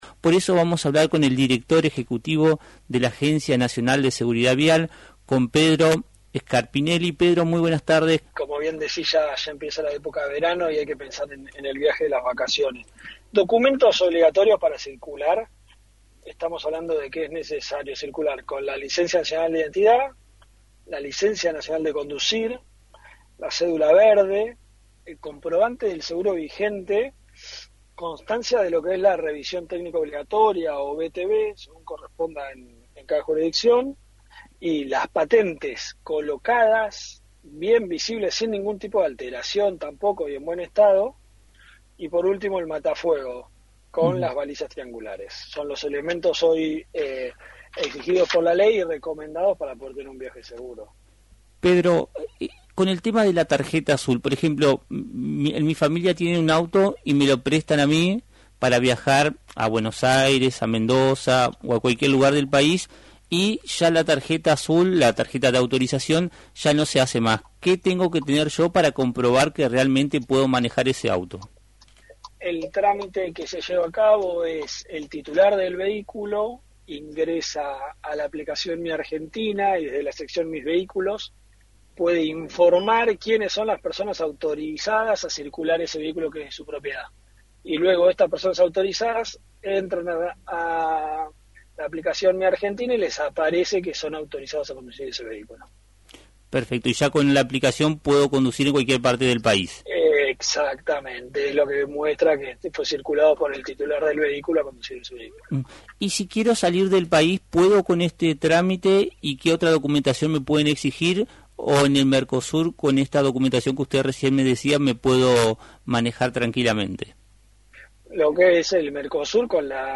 Pedro Scarpinelli, director ejecutivo de la Agencia Nacional de Seguridad Vial,  destacó en Radio Victoria la importancia de llevar la documentación obligatoria para circular de manera segura y legal en rutas nacionales y del Mercosur.